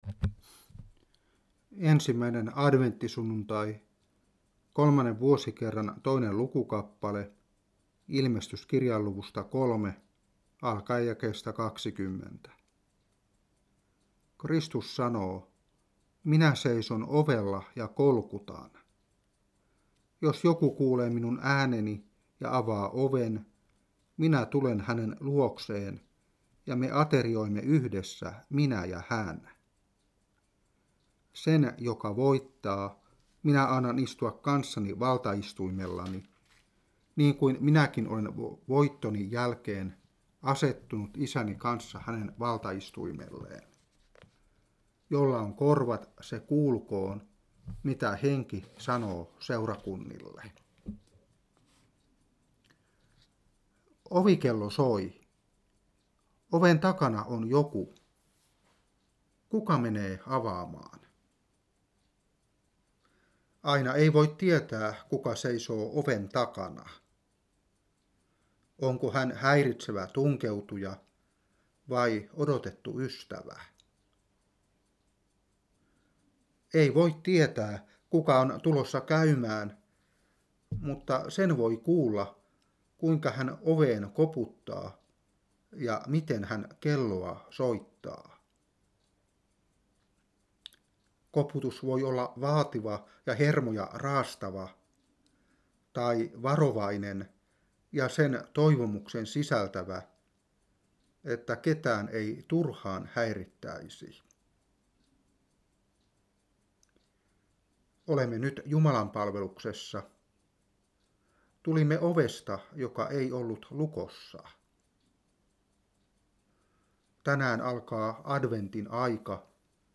Saarna 2011-11.